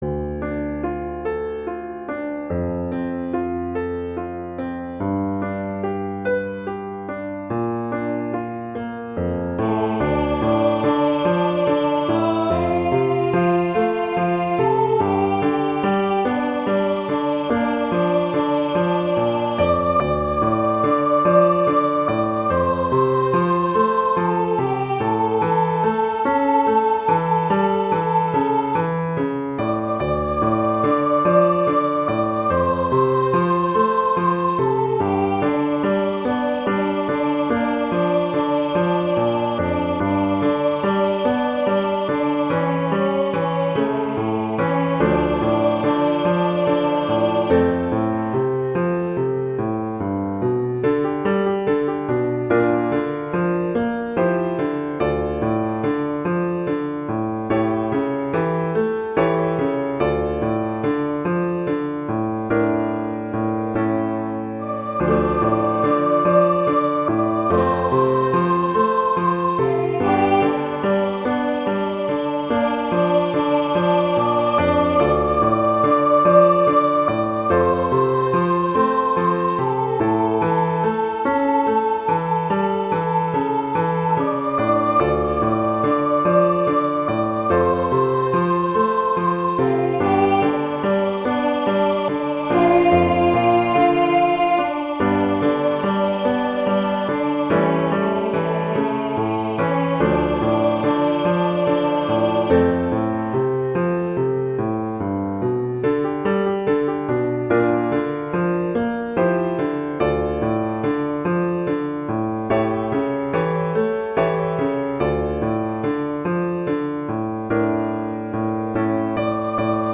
arrangement for voice, piano or other instruments
traditional, christian, inspirational, hymn, children
D minor
♩. = 48 BPM